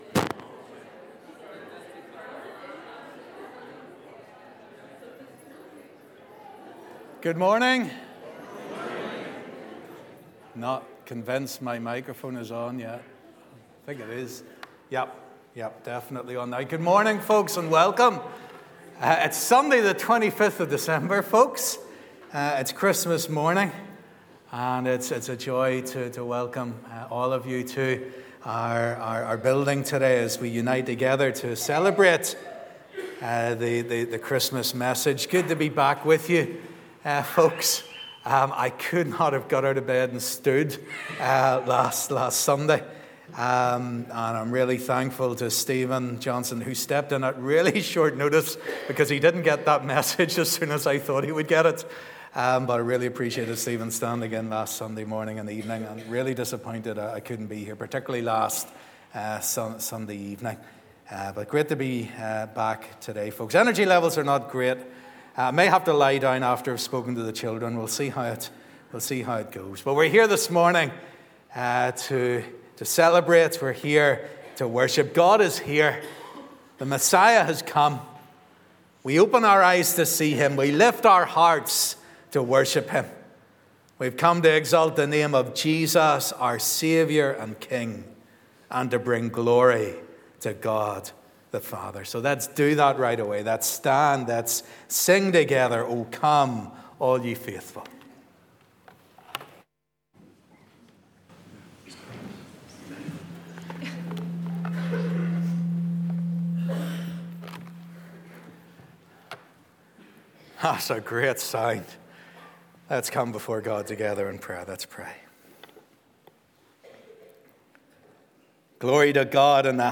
Christmas Day Service 2022